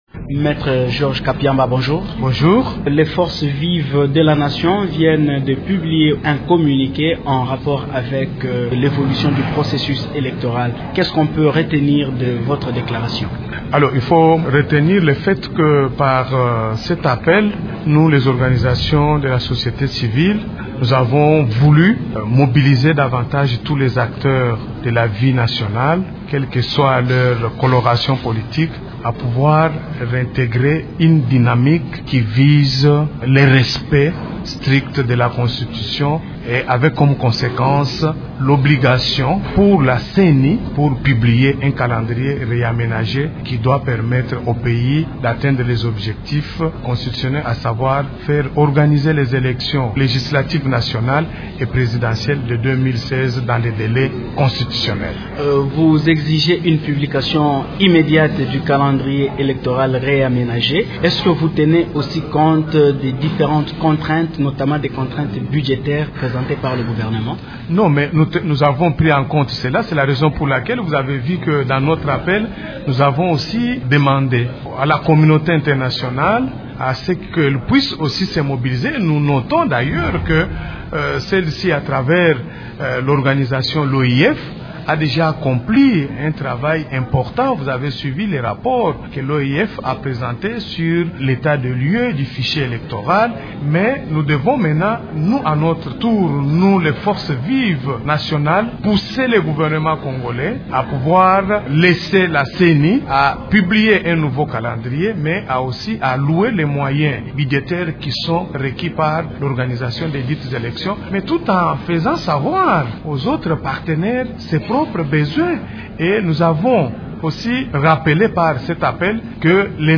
Il est notre invité.